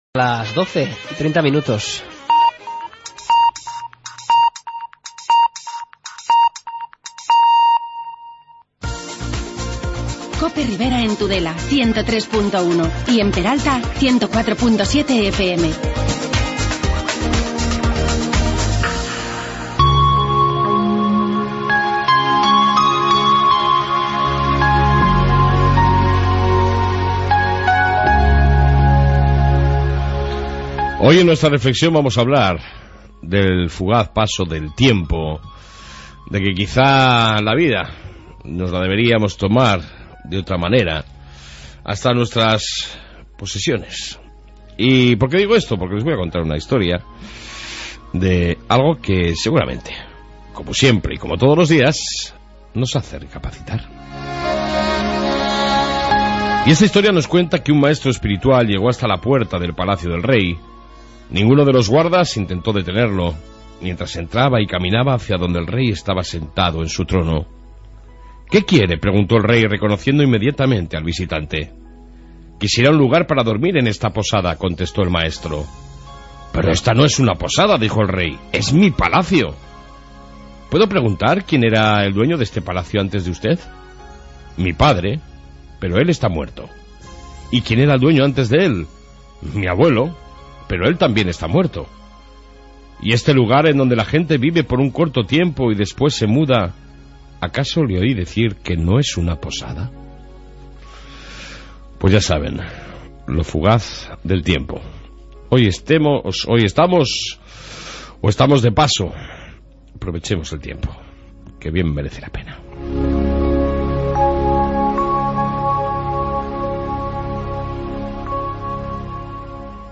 AUDIO: En esta 1 parte Reflexión matutina, Policía municipal y amplia entrevista con el nuevo concejal de Festejos Javier Gómez Vidal